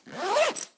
action_open_backpack_0.ogg